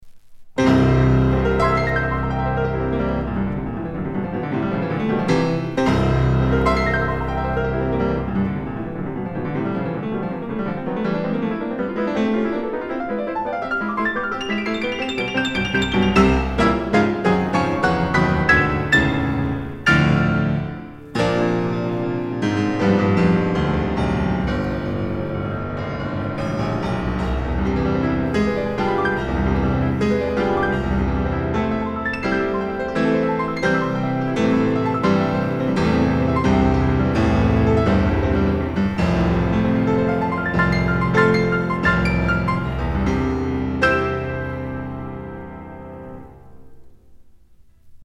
CB2の方がピアノやブラスの音がより輝いて聞こえます。
比較試聴した時の楽曲は全てDSD 5.6M で録音しました。
クラシック、ピアノ独奏